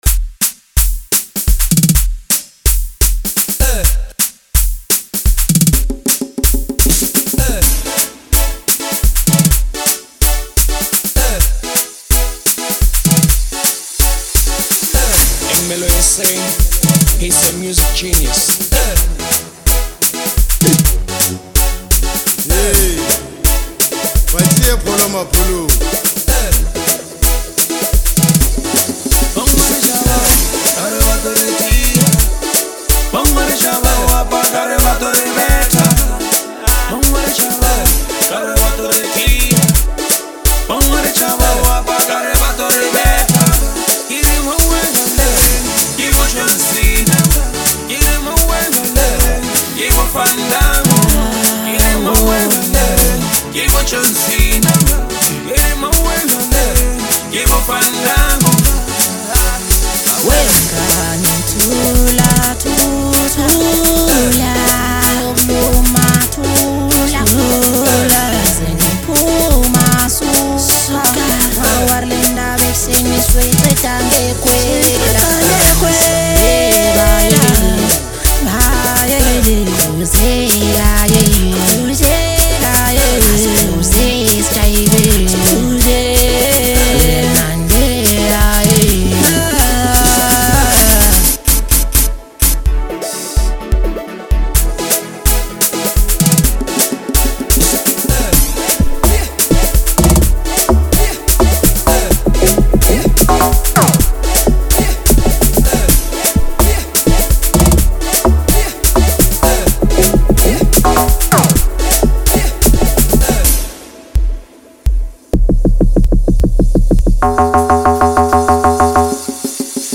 an electrifying track